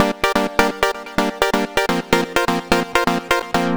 Sync Pad Gm 127.wav